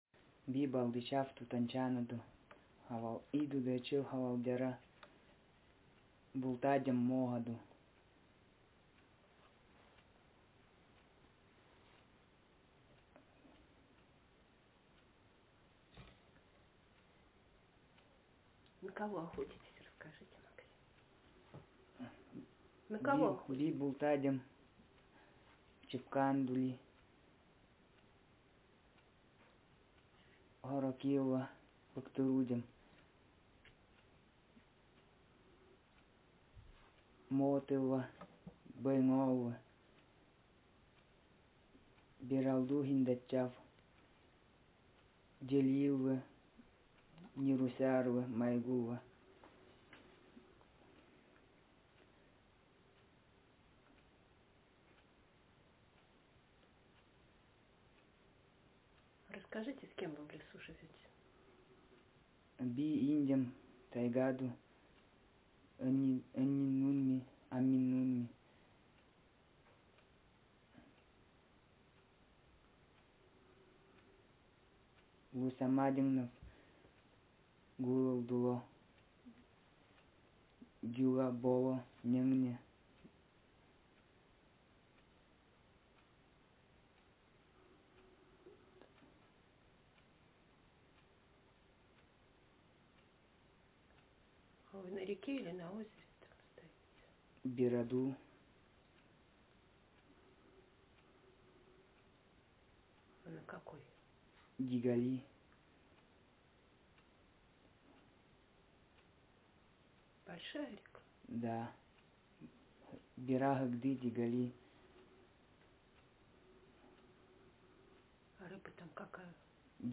Speaker sexm
Text genreconversation